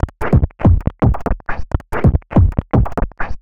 tx_perc_140_grundle1.wav